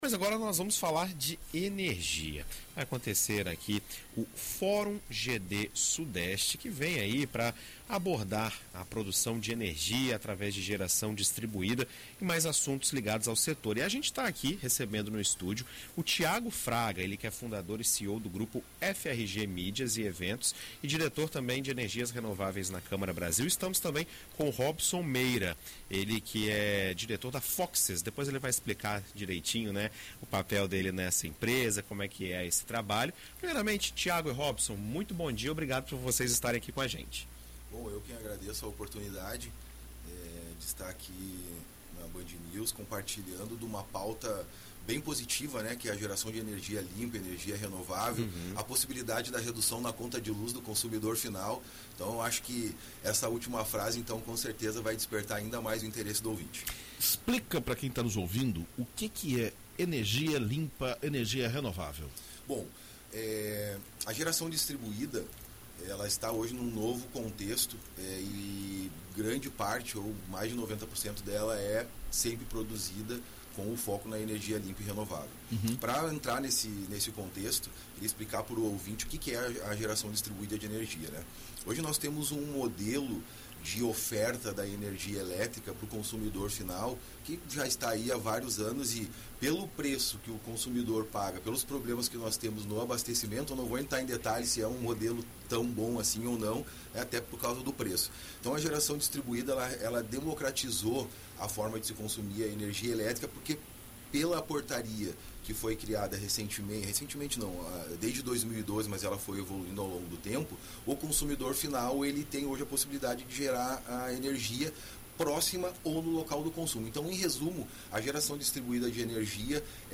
Em entrevista à BandNews FM ES